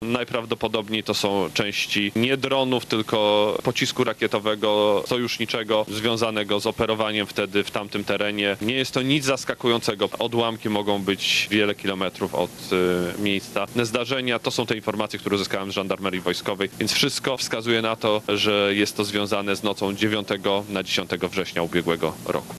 – Najprawdopodobniej to są części nie drona, tylko sojuszniczego pocisku rakietowego, związanego z operowaniem wtedy w tamtym terenie – mówił szef MON.